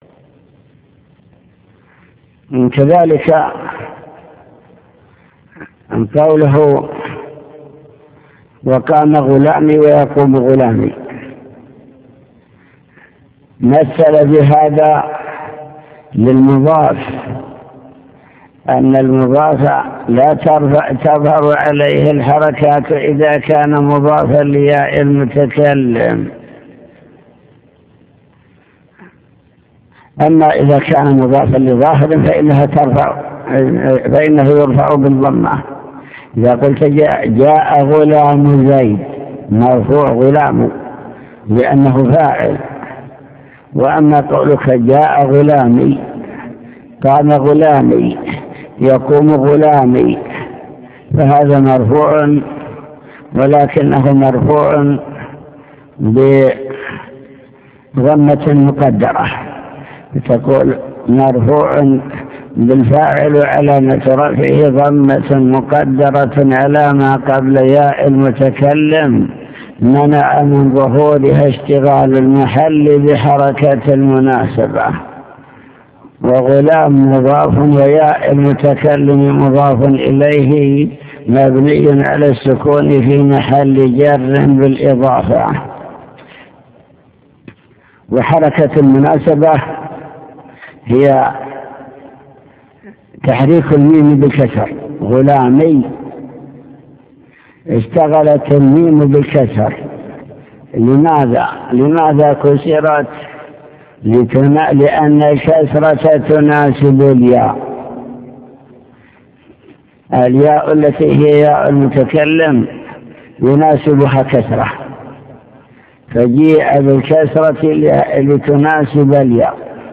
المكتبة الصوتية  تسجيلات - كتب  شرح كتاب الآجرومية باب الفاعل أقسام الفاعل